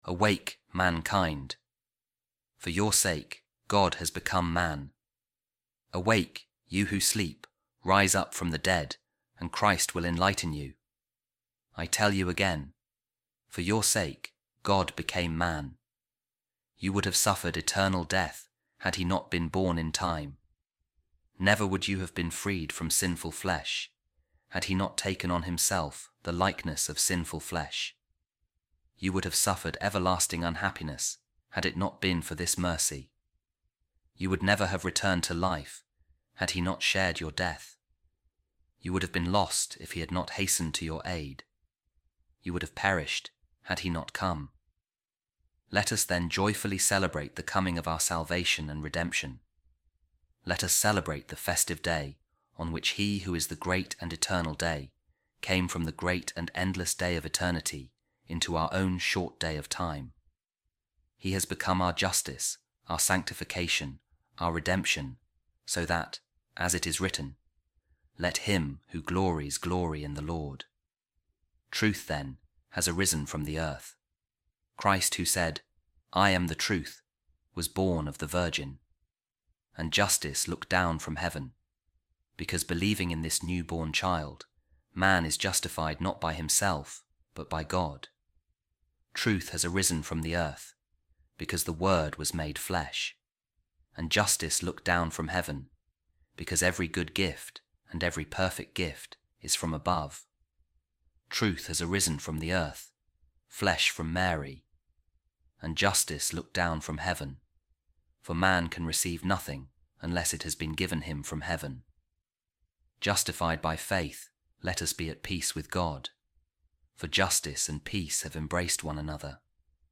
A Reading From A Sermon By Saint Augustine | Truth Has Arisen From The Earth And Justice Has Looked Down From Heaven
office-readings-advent-december-24-saint-augustine.mp3